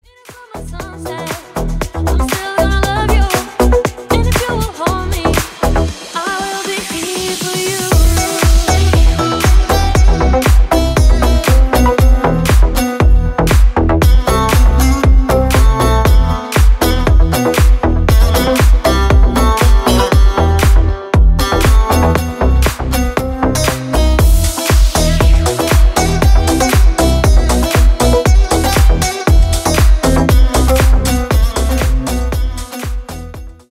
• Качество: 192, Stereo
deep house
восточные мотивы
красивая мелодия
красивый женский голос
Классный рингтон в стиле deep house.